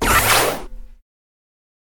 rocket.ogg